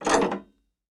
AnchorLever.ogg